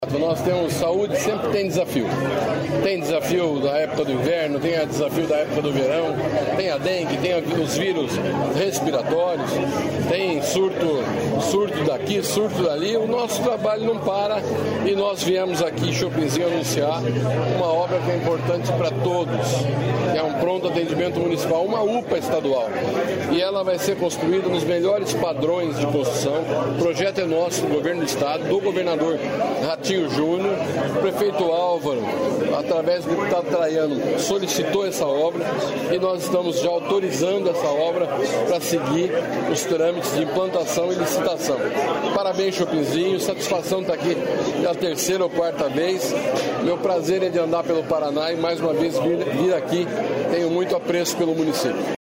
Sonora do secretário da Saúde, Beto Preto, sobre construção de Pronto Atendimento Municipal em Chopinzinho